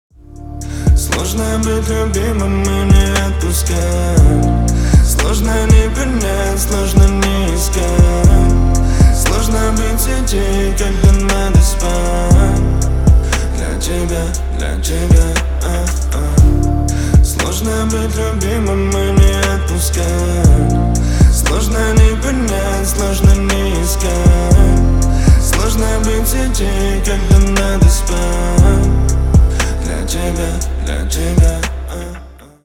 Поп Музыка
грустные
спокойные